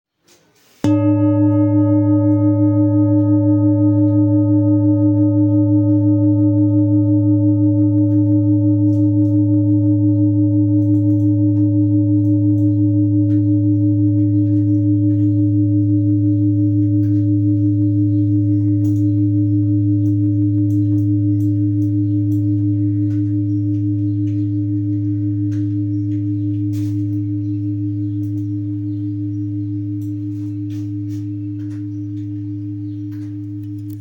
Full Moon Bowl, Buddhist Hand Beaten, Moon Carved, Antique Finishing, Select Accessories
Material Seven Bronze Metal
This is a Himalayas handmade full moon singing bowl. The full moon bowl is used in meditation for healing and relaxation sound therapy.